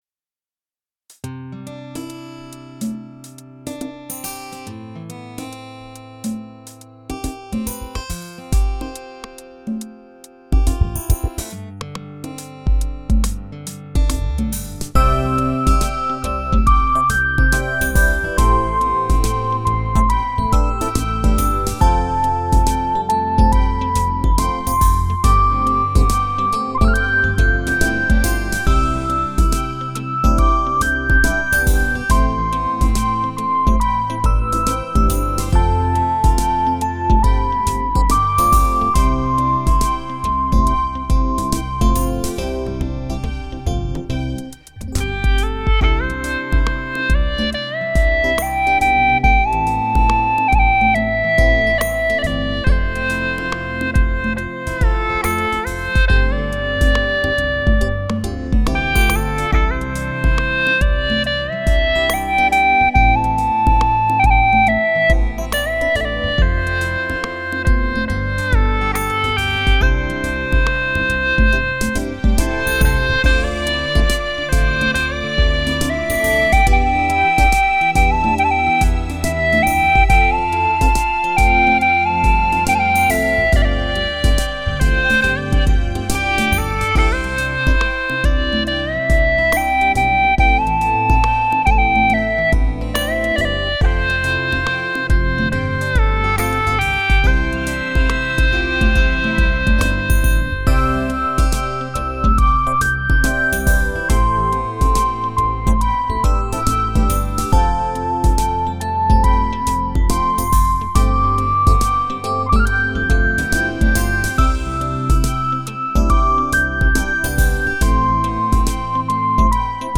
3周前 纯音乐 6